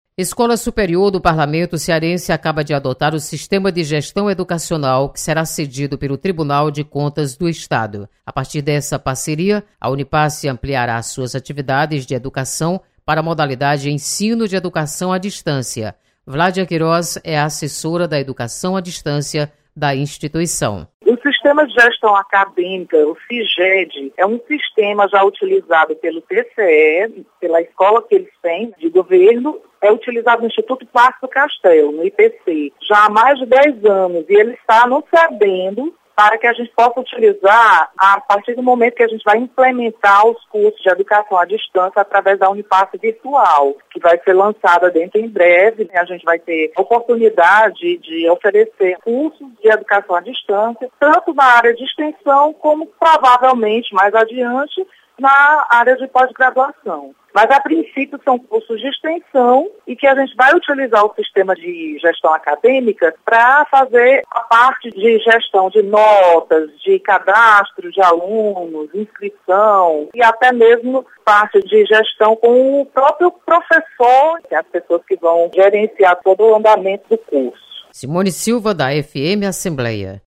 Você está aqui: Início Comunicação Rádio FM Assembleia Notícias Unipace